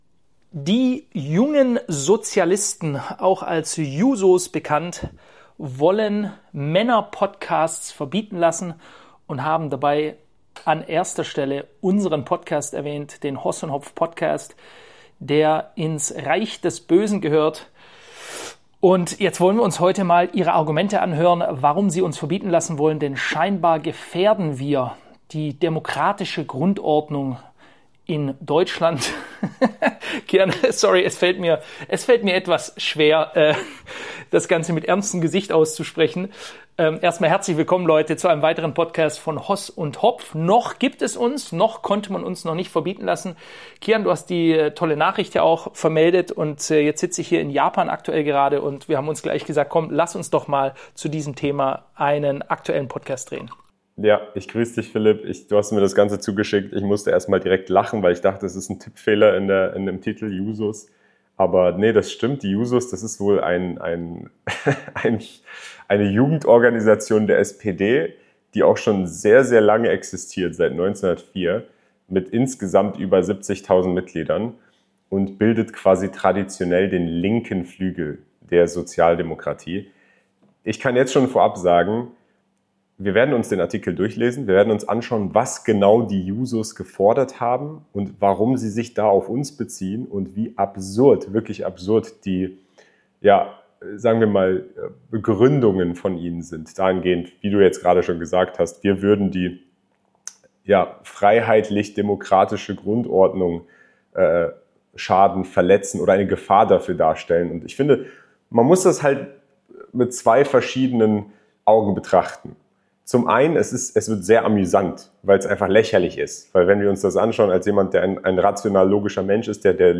Eine facettenreiche Diskussion über Grundrechte, politische Jugendarbeit, gesellschaftliche Debatten und die Zukunft freier Medien.